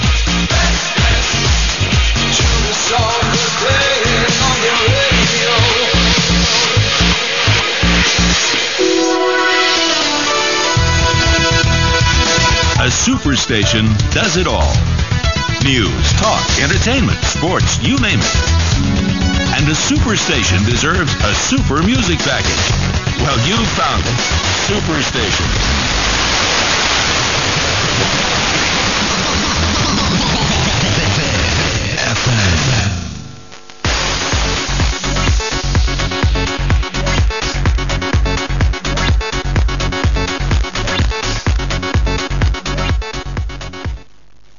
Signals were mostly strong and clear.
• 91.6 MHz - VFM, Vinkovci, Croatia. "Super station"-jingles in English. This is now a dance-station! (www)- 18.23